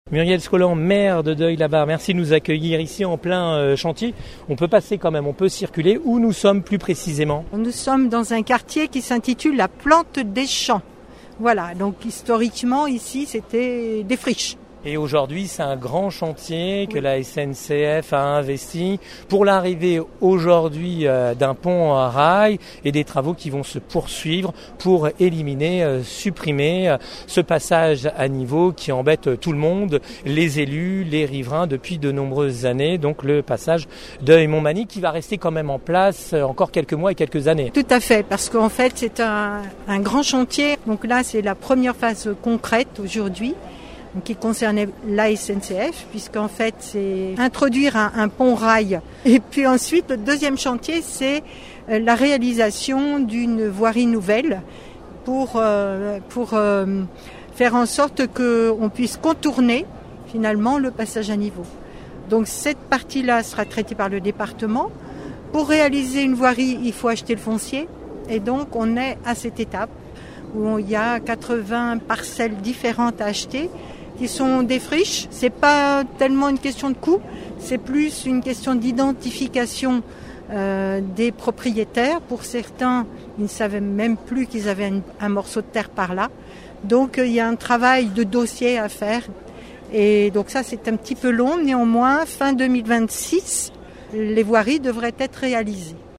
Sur place les réactions de :
Muriel SCOLAN, maire de Deuil-La-Barre